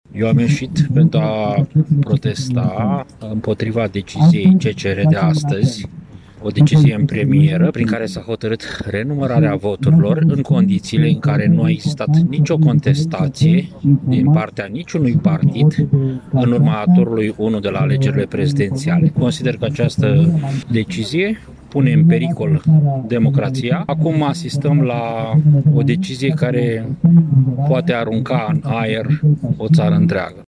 Pe de altă parte, în Piața Unirii din Iași este prezent și un grup de aproximativ 20 de persoane care își exprimă dezacordul cu hotărârea de astăzi a Curții Constitționale, de renumărare a voturilor, aceștia considerând că se încalcă unul din principiile fundamentale ale Constituției, respectiv acela de a vota și de a fi votat.
28-nov-ora-21-protestatar-CCR.mp3